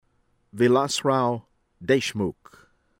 DESAI, NITIN NIH-tihn   dehs-EYE